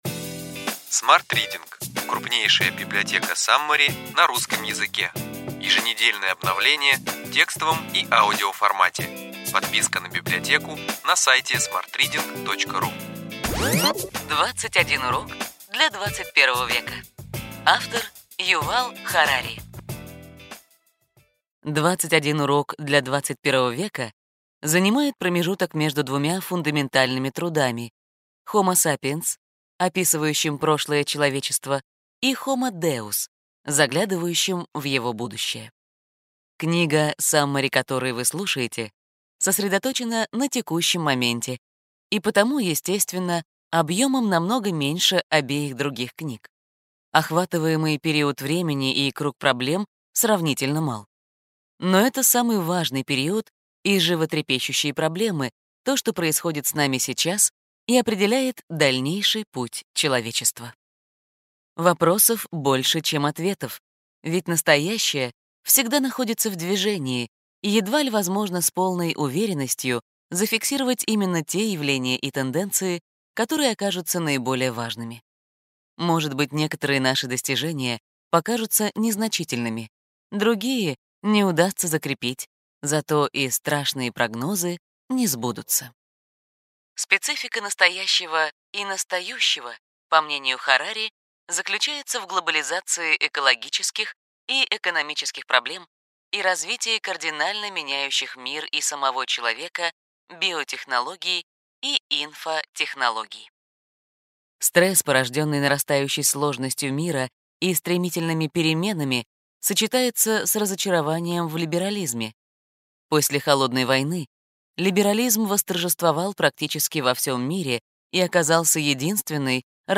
Аудиокнига Ключевые идеи книги: 21 урок для XXI века.